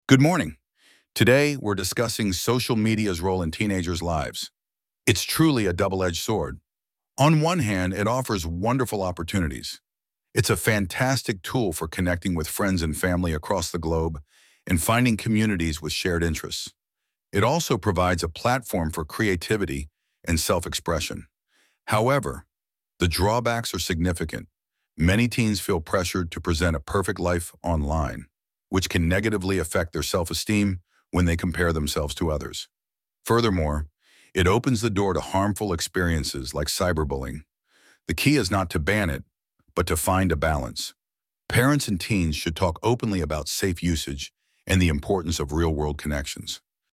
I. You will hear a youth psychologist talking about the effects of social media on teenagers.